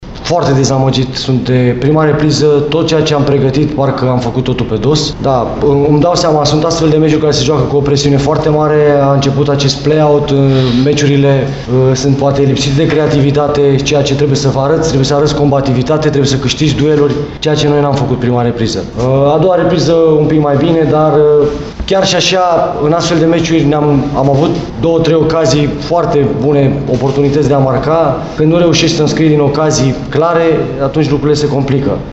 De partea cealaltă, tehnicianul oaspeţilor, Ovidiu Burcă, s-a arătat dezamăgit de ce a arătat echipa sa, mai ales în prima repriză: